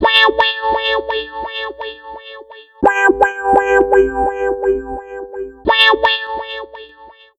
Index of /90_sSampleCDs/Zero-G - Total Drum Bass/Instruments - 1/track35 (Guitars)
03 Wow Bow 170 G.wav